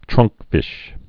(trŭngkfĭsh)